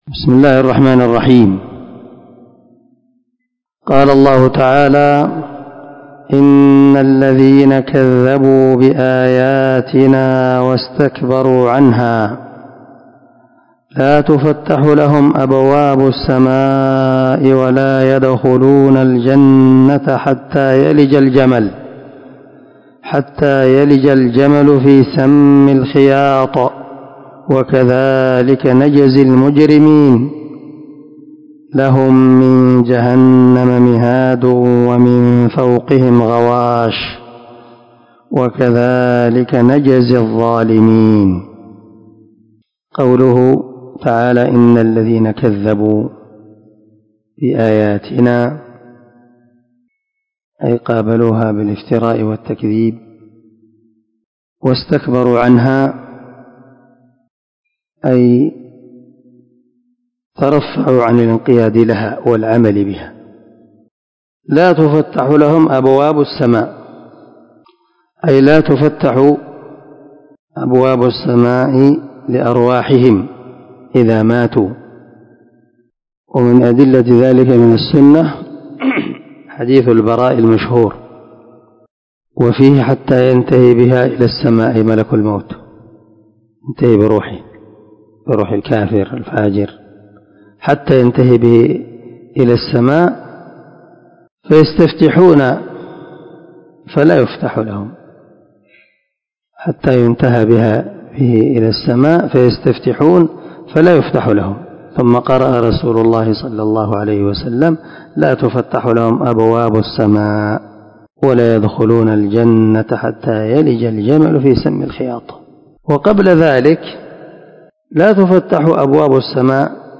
عنوان الدرس:
✒ دار الحديث- المَحاوِلة- الصبيحة.